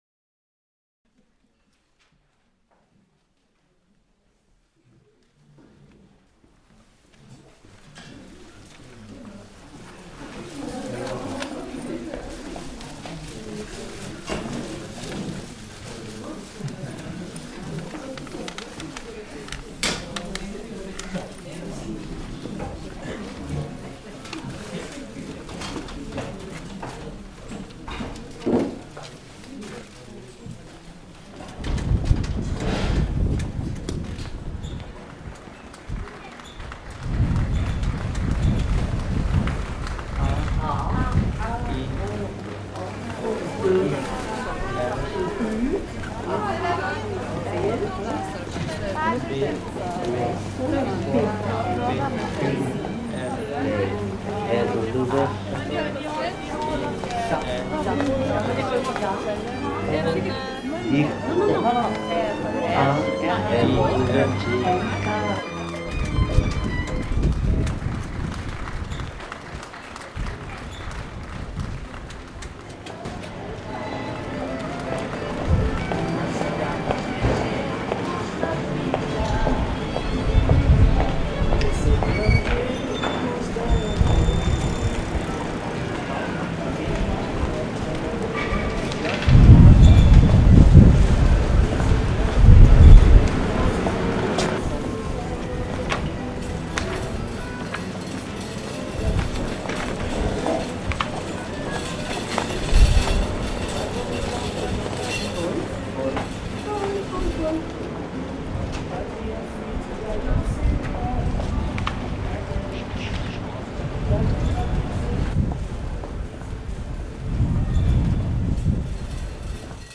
Des montées de paroles à l’écoute d’elles-même dans un jeu anxieux, s’apaisent brusquement d’instants précieux d’exercices musicaux. Les pas, les roues et l’air distrait de la rue traversent l’ensemble qui forme une grande vague balayée fréquemment par le vent.
Les espaces dispersants croisent les espaces attentifs de concert, cours de techniques vocales et cours de langue1 où s’élabore une évolution des phonèmes épars aux phrases hésitantes puis jeux d’identités.